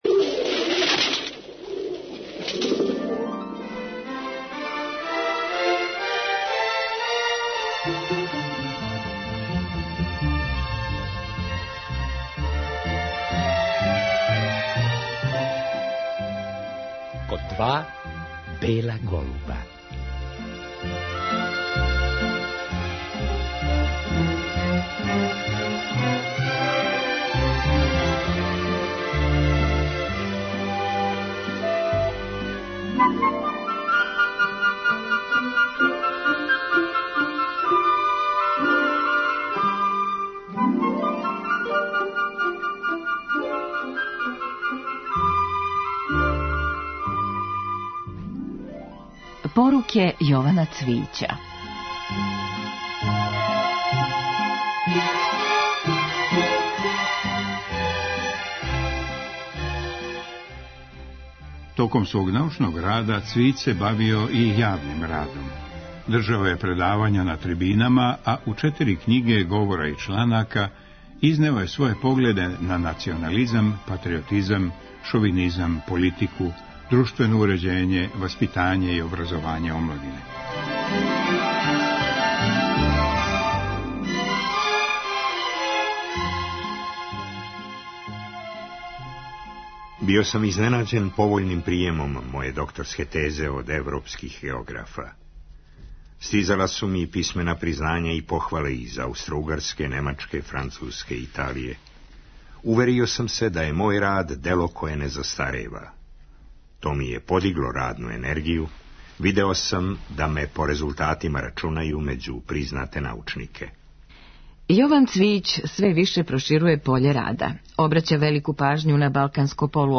Гост емисије је био 1990. године. Део тог разговара поново емитујемо.